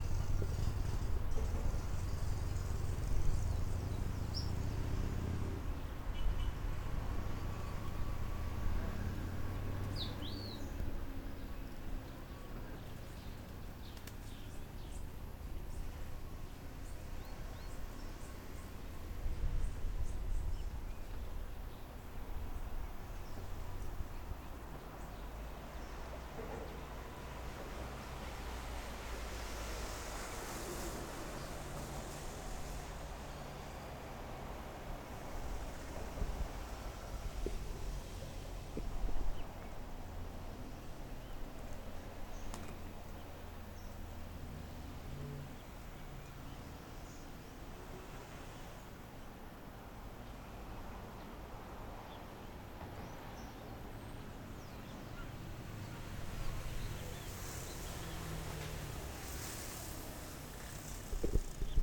Un mapa sonoro es una técnica acústica para conocer los sonidos de un lugar, comunidad o ciudad; ubica los sonidos geográficamente.
Paisaje sonoro puente Río Térraba PUNTARENAS